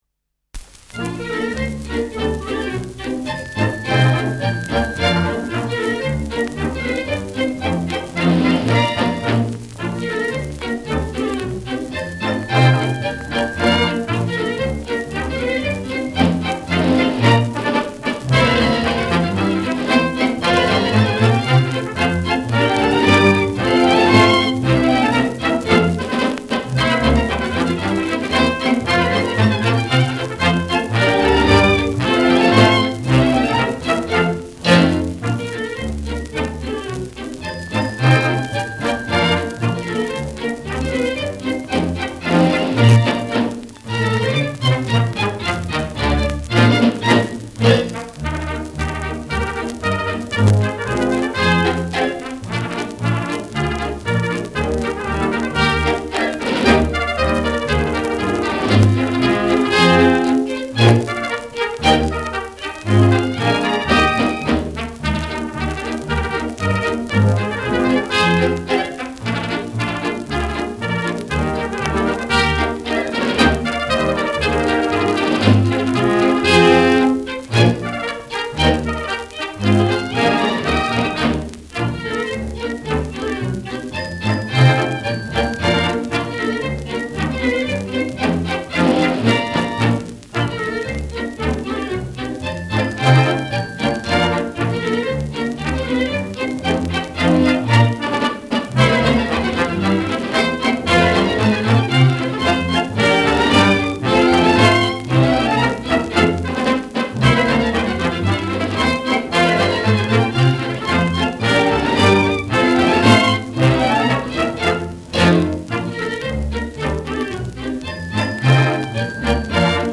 Schellackplatte
leichtes Rauschen : leichtes Knistern
Schlagwerk mit Glockenspiel.
[Berlin] (Aufnahmeort)